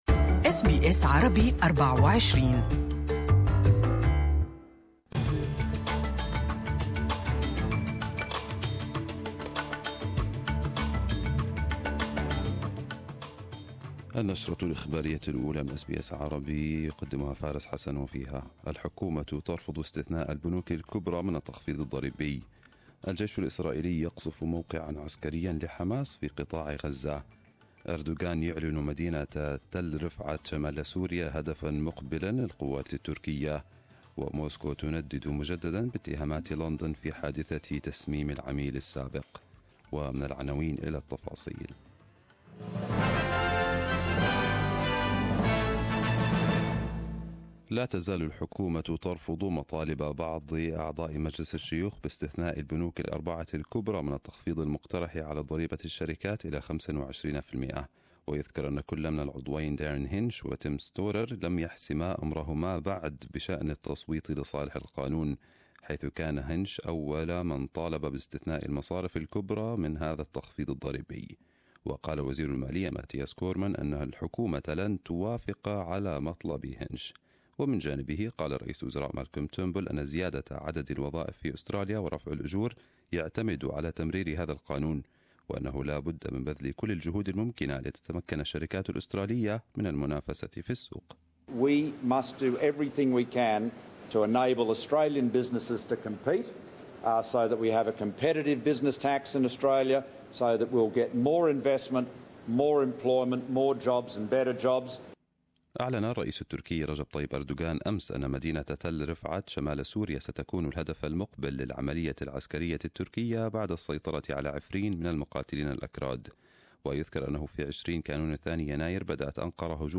Arabic News Bulletin 26/03/2018